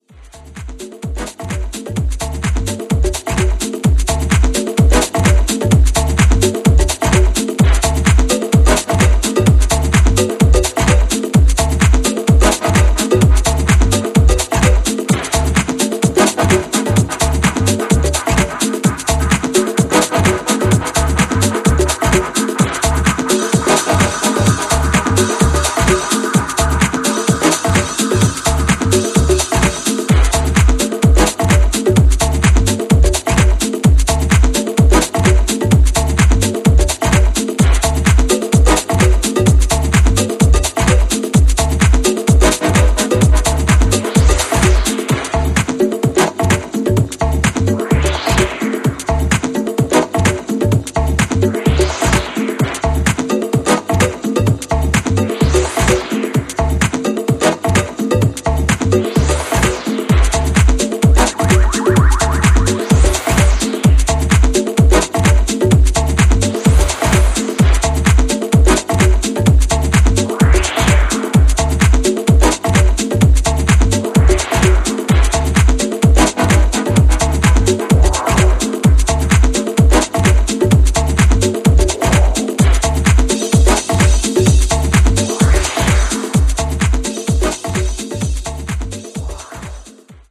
ジャンル(スタイル) TECH HOUSE / DEEP HOUSE / MINIMAL